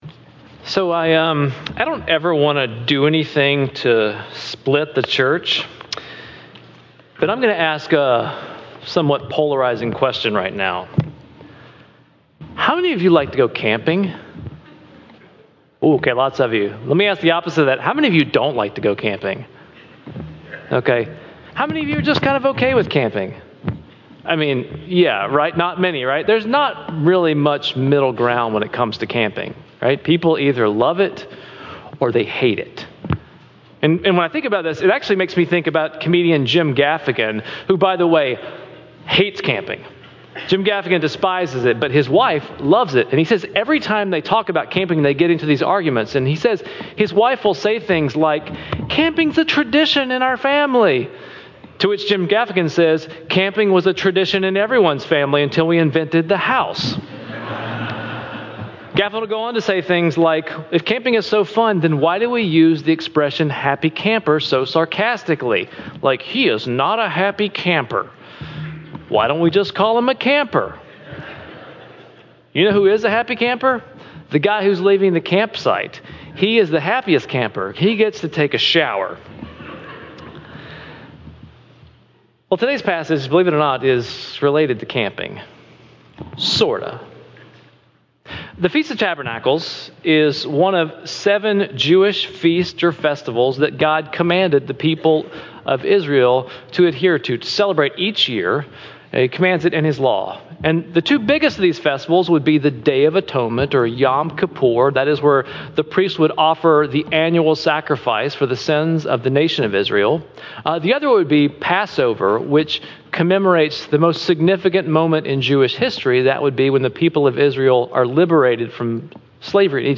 2026-03-22-SermonAudio-CD.mp3